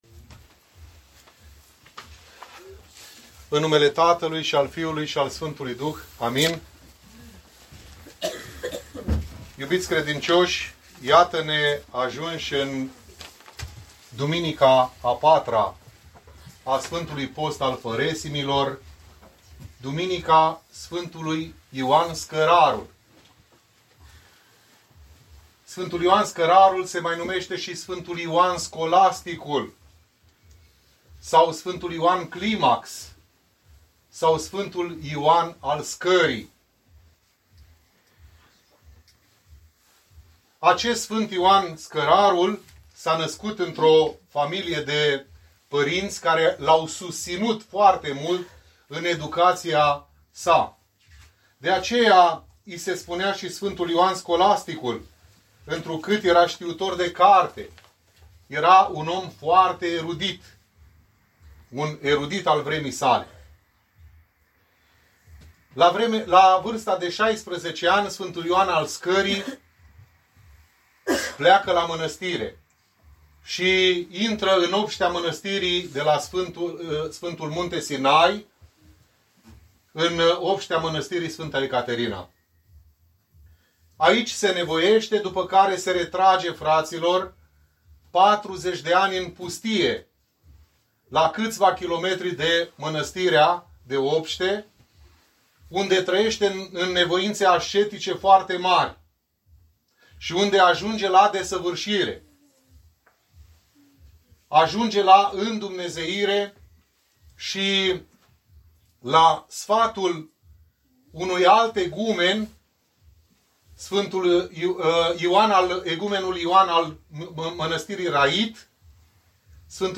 Predica